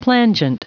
Prononciation du mot plangent en anglais (fichier audio)
Prononciation du mot : plangent